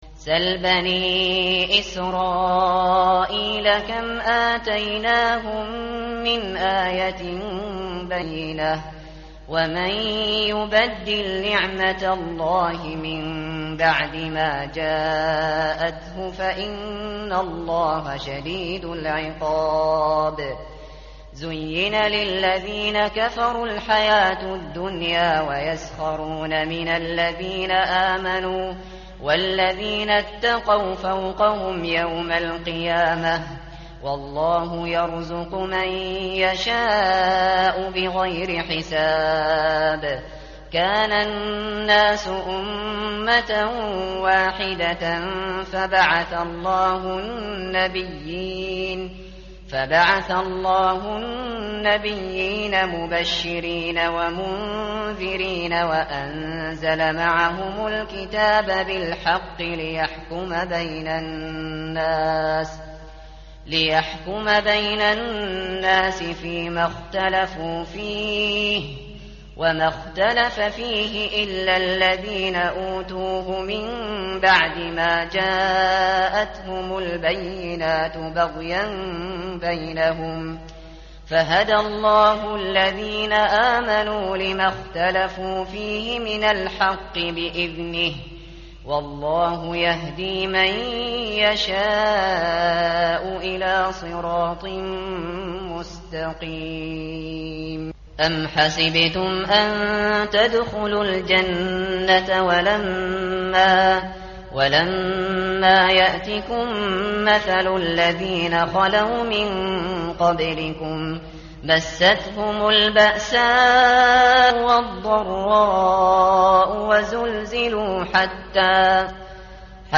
متن قرآن همراه باتلاوت قرآن و ترجمه
tartil_shateri_page_033.mp3